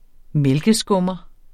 mælkeskummer substantiv, fælleskøn Bøjning -en, -e, -ne Udtale [ ˈmεlgəˌsgɔmʌ ] Oprindelse kendt fra 2000 Betydninger håndbetjent eller elektrisk apparat som bruges til at piske mælk til kaffe, cappuccino mv.